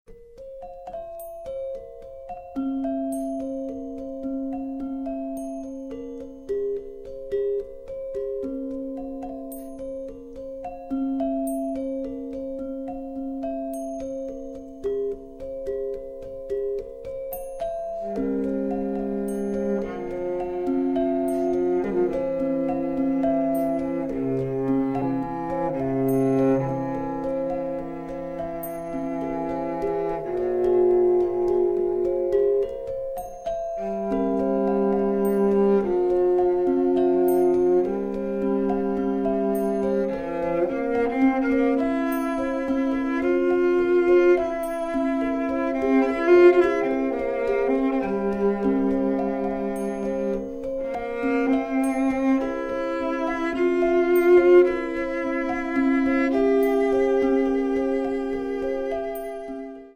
Gender, viola, cello, and percussion